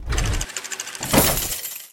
safebox_open.mp3